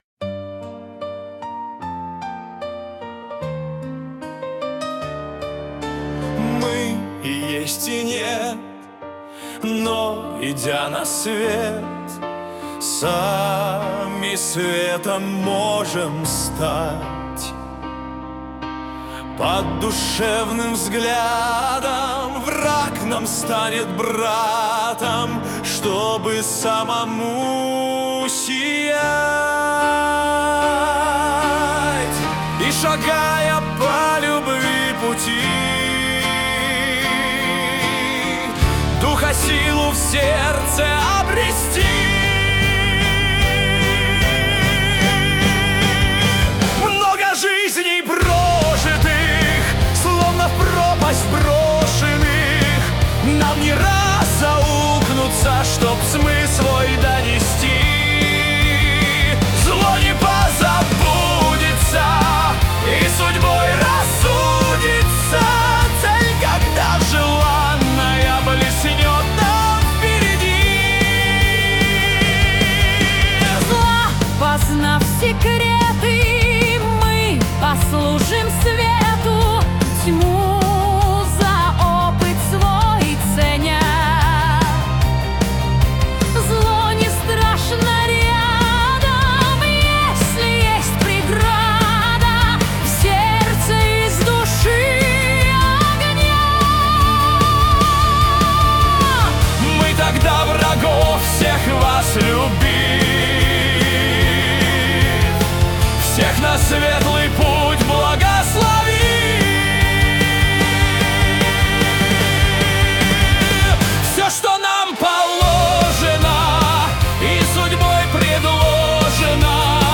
Для Медитаций